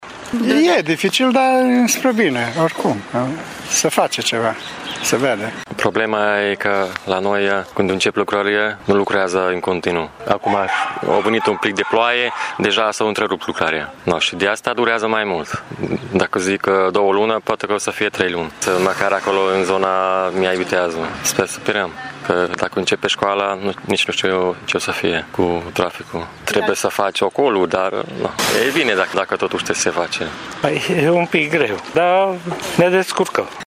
Pentru șoferi sunt mai multe inconveniente fiind obligați să circule pe rute ocolitoare, însă, se bucură că ”se face ceva”: